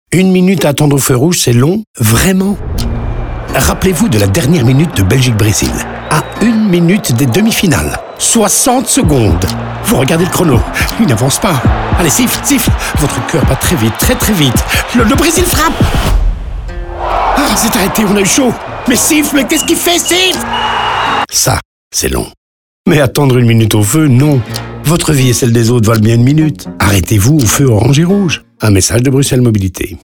Ces 3 sujets sont déclinés en radio, OOH et en social.